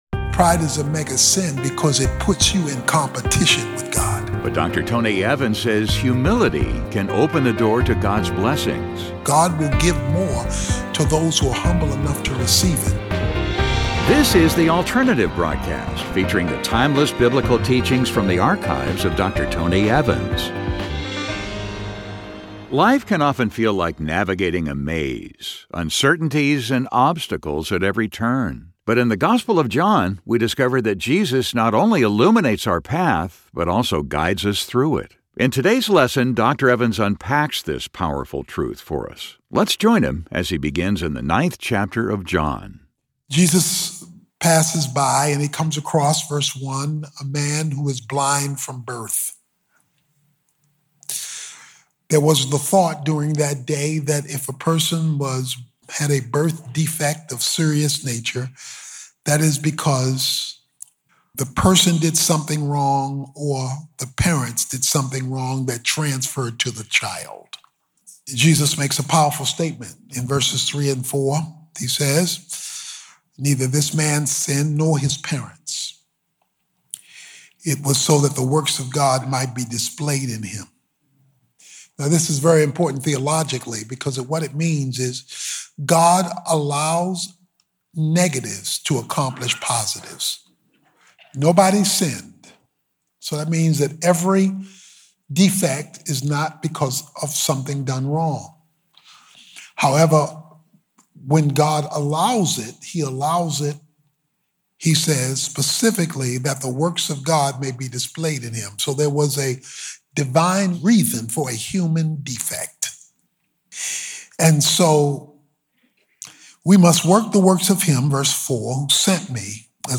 In this message, Dr. Tony Evans shares how Jesus does exactly that, helping you move forward with clarity and purpose.